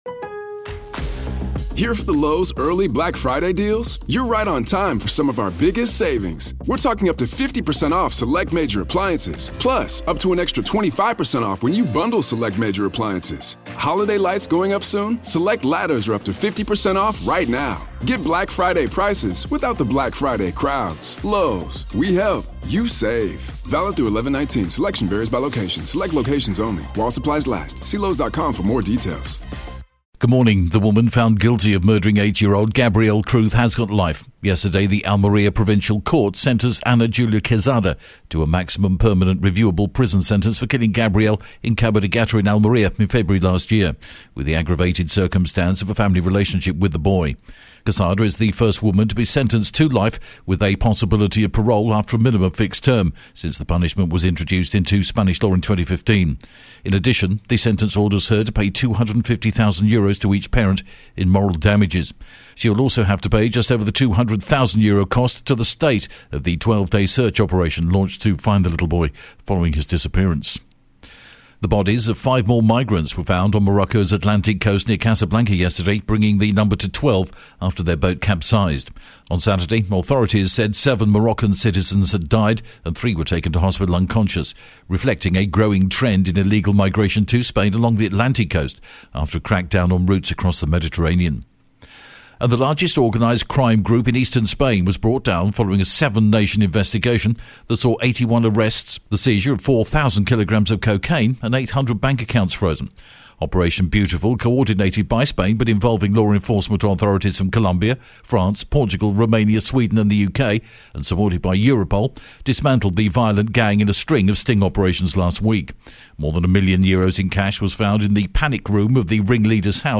The latest Spanish news headlines in English: October 1st